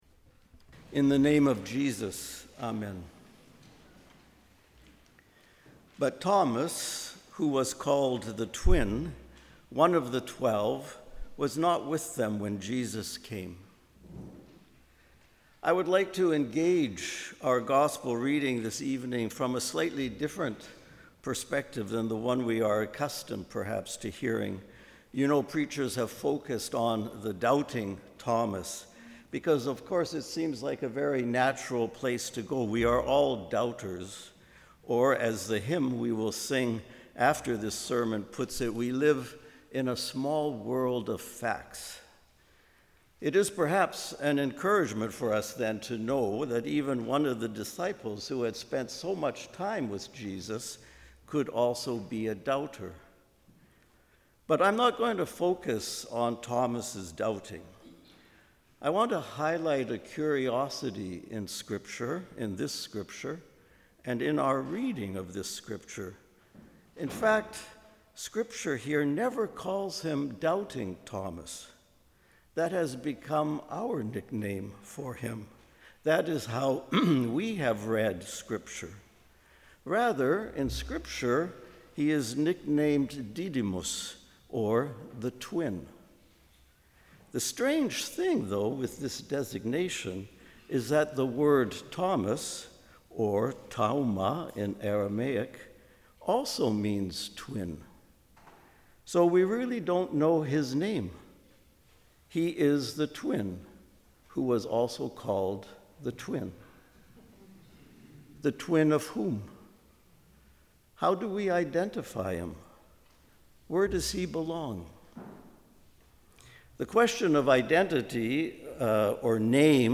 Sermon: ‘We are all twins’
Second Sunday of Easter.